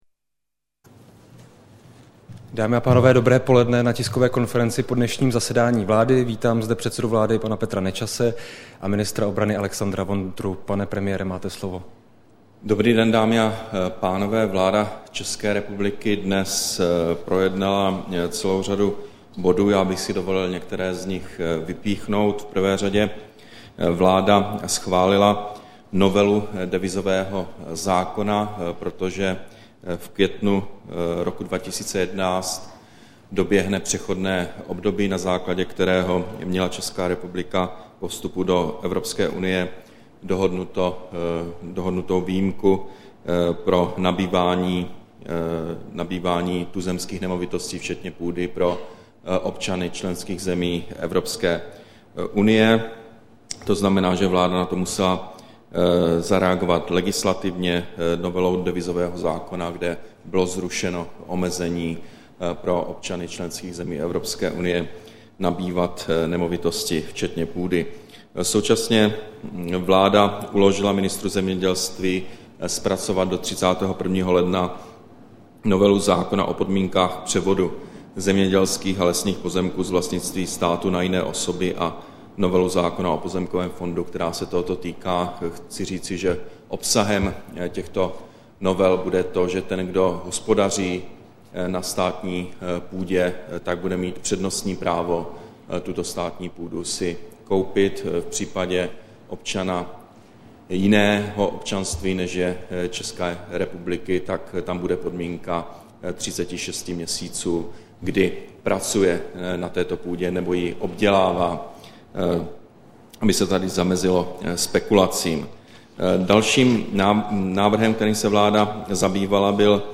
Tisková konference po jednání vlády, 24. listopadu 2010